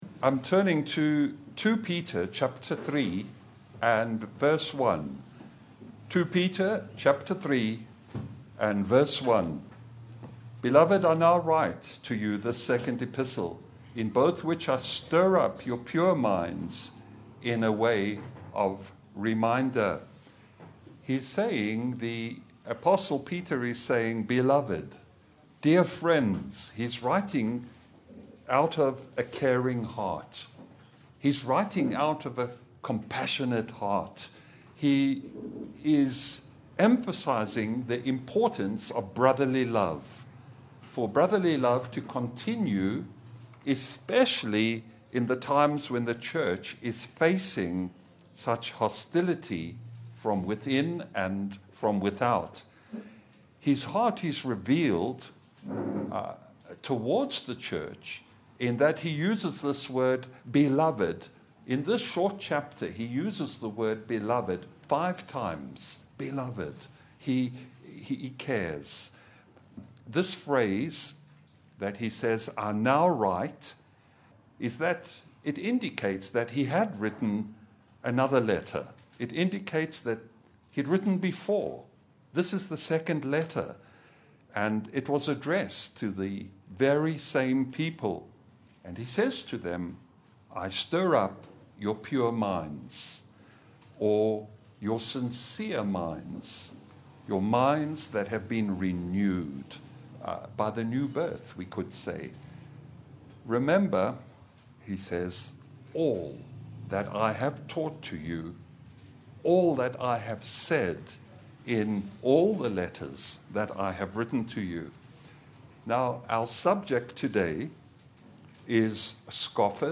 Passage: 2Peter 3:1 Service Type: Sunday Bible fellowship